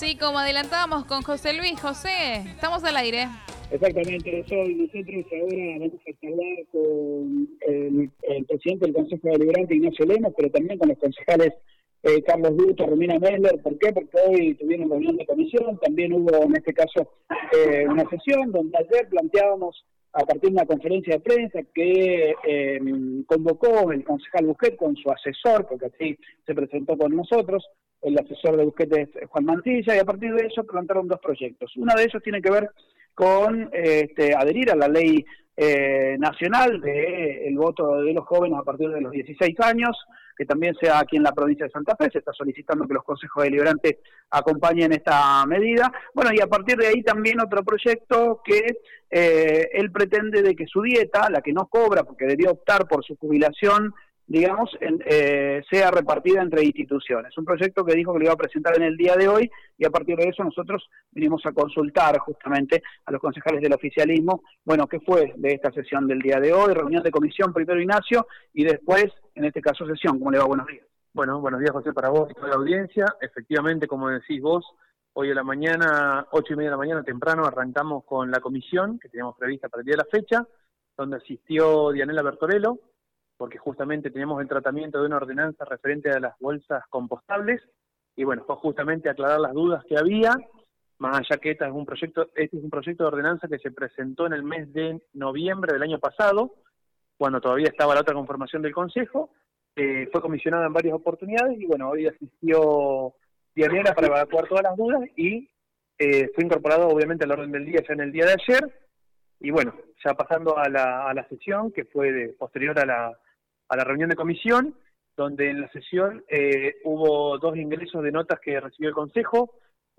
Escucha la entrevista que realizamos con el presidente del Concejo Ignacio Lemos y los Concejales Romina Meshler y Carlos Dutto.